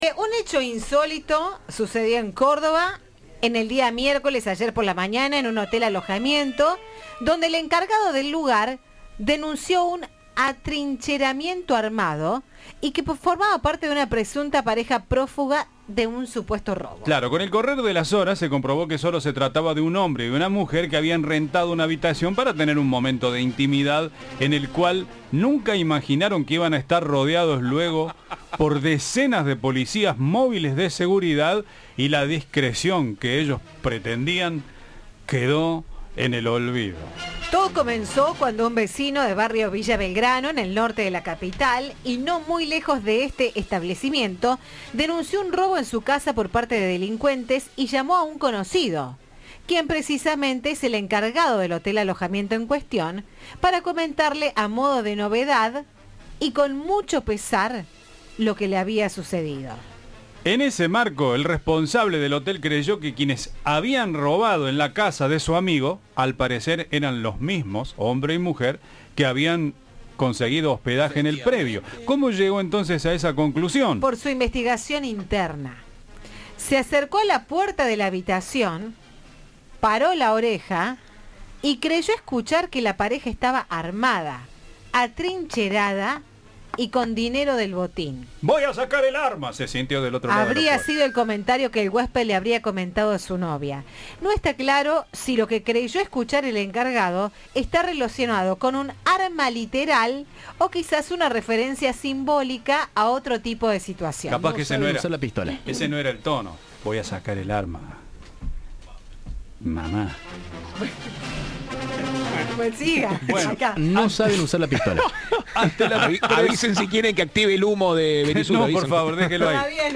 Ante esto un ex dueño de uno habló con Cadena 3.